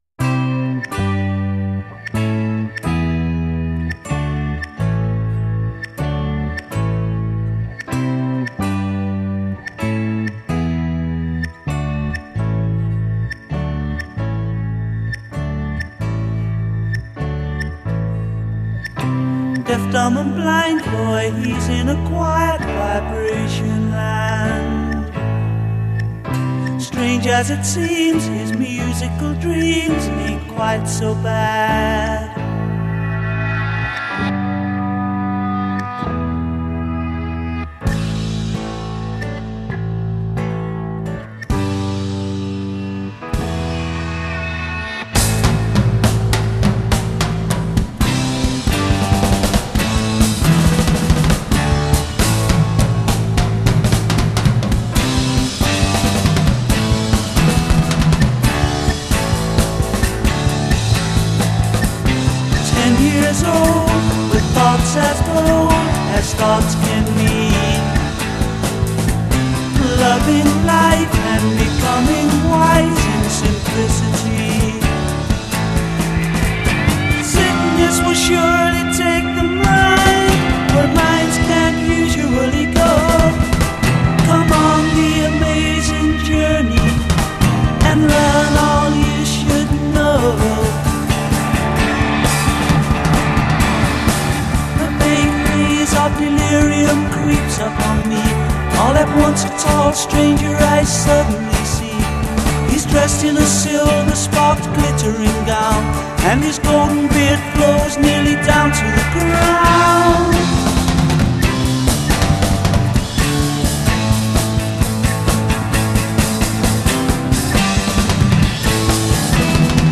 Recorded at I.B.C. Studios, London
vocal against descending chromatic line
A' bridge   subdued verse chord progression
A' bridge   ensemble over verse progresssion